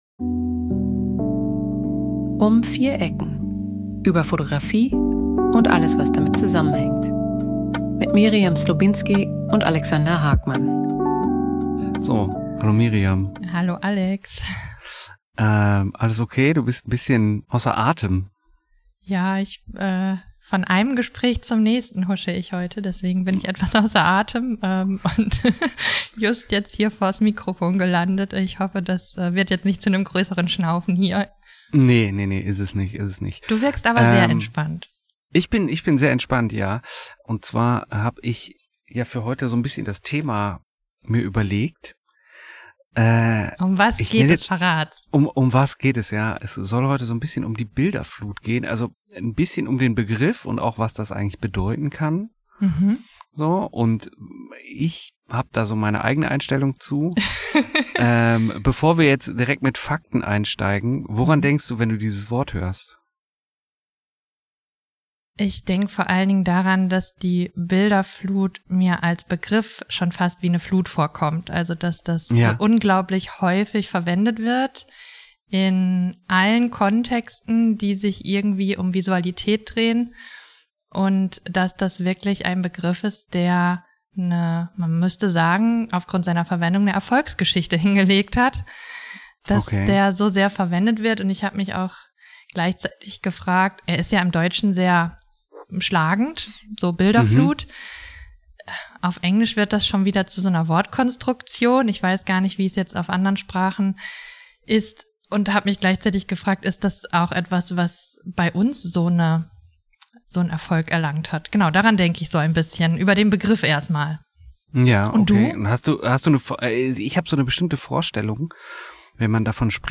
Ein Gespräch über die Bilderflut. Wie lässt sie sich beschreiben, wie begreifen?